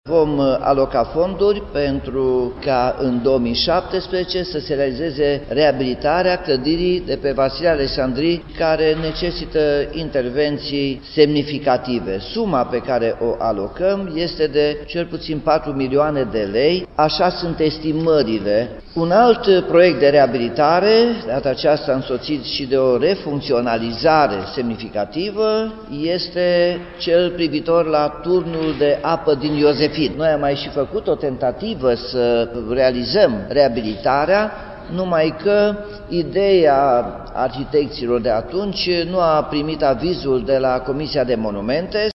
Primarul Nicolae Robu spune că este important ca aceste clădiri să fie reparate, nu doar ca să arate bine, ci și pentru a se păstra cât mai mult timp: